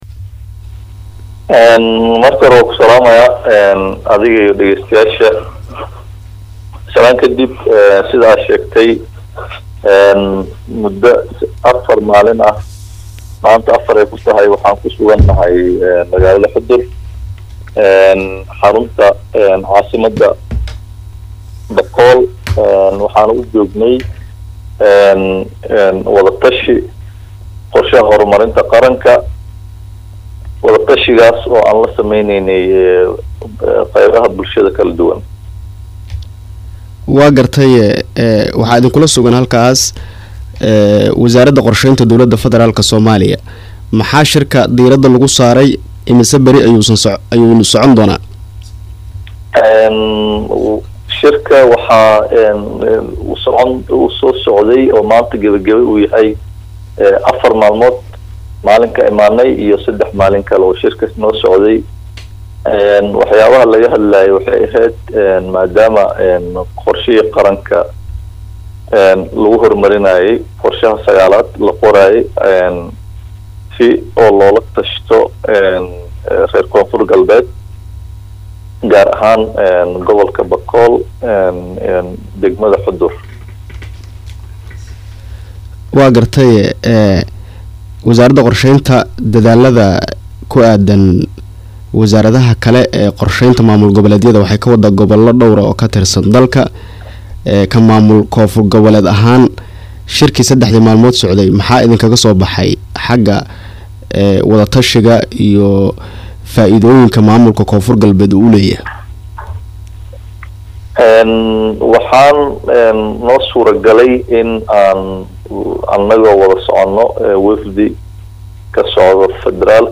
Wasiirka Wasaaradda Qorsheynta Koofur Galbeed Axmed Madoobe Nuunow ayaa Radio Muqdisohu wareysi uu siiyey waxaa uu kaga hadlay waxyaabaha Shirkaas looga hadlay.